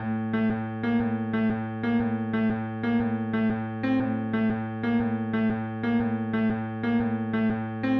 描述：一个简短的奇怪音乐样本包括鼓，贝司，钢琴和合成器的噪音。在FL Studio中创建。
标签： 钢琴 FL-工作室 音乐 采样 歌曲
声道立体声